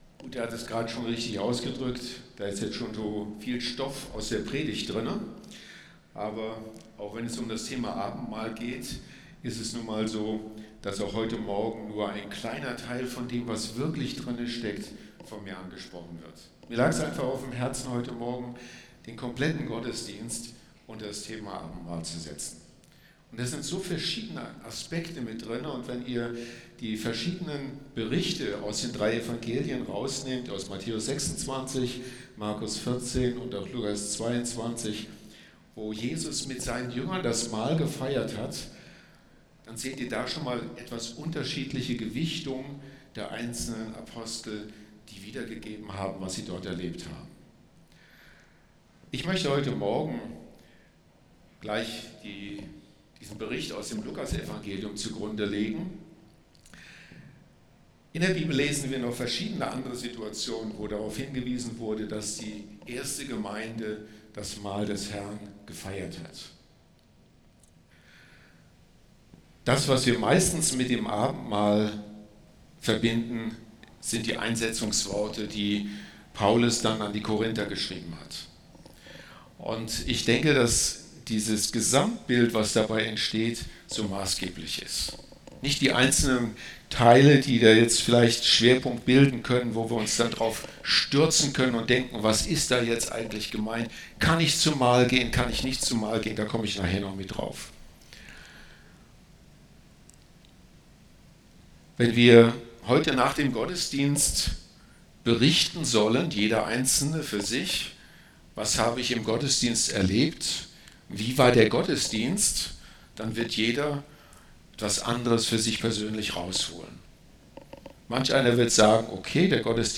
Genre: Predigt.